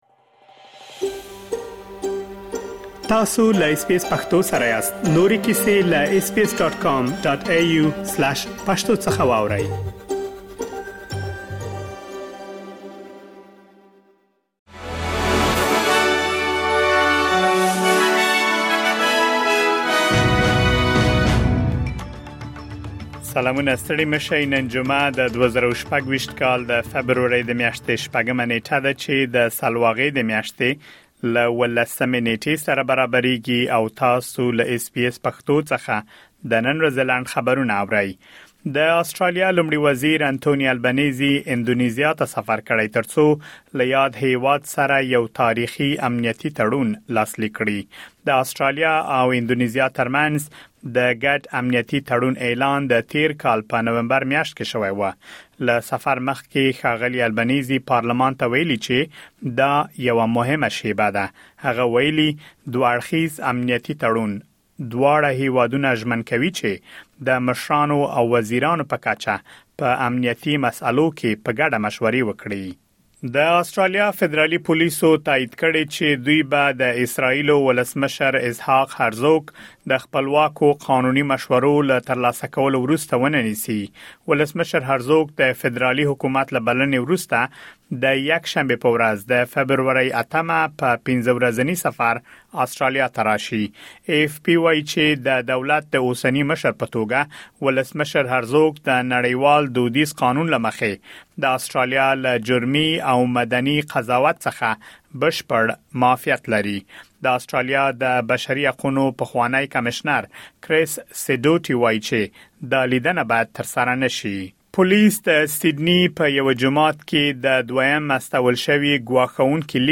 د اس بي اس پښتو د نن ورځې لنډ خبرونه |۶ فبروري ۲۰۲۶